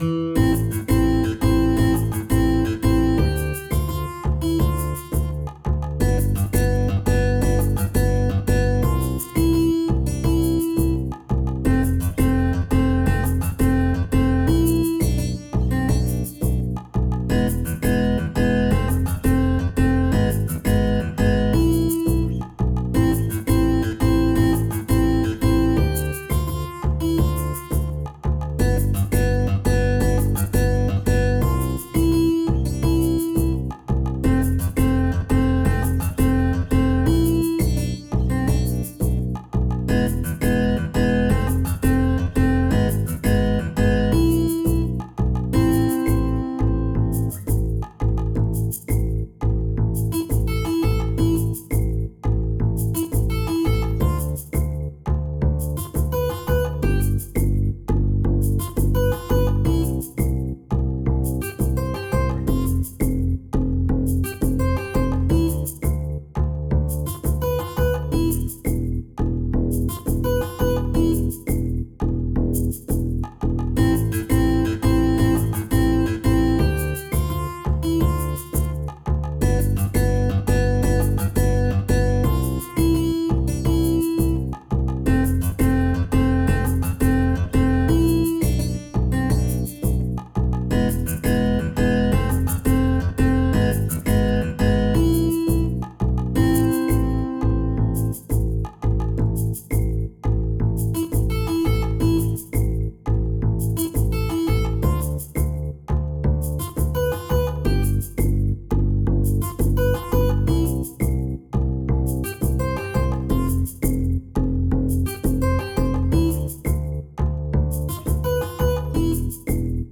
acoustic, nylon string guitar